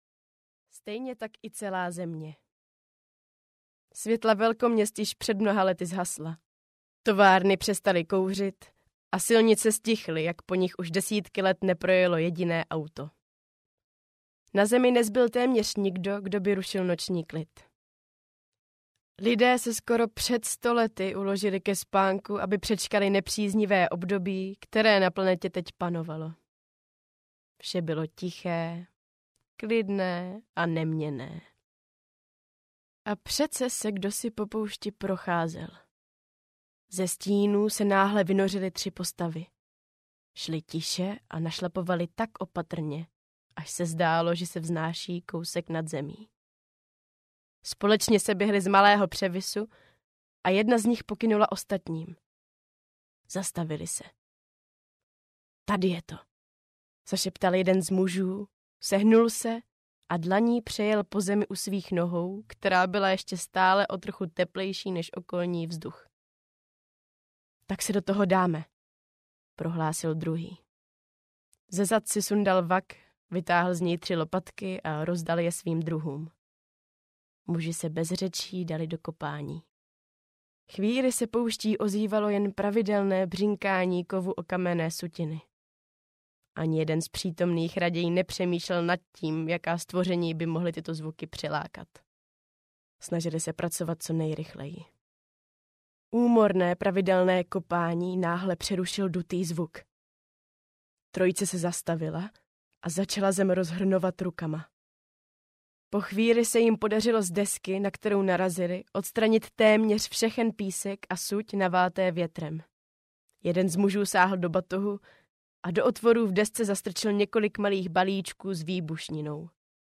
Fialová audiokniha
Ukázka z knihy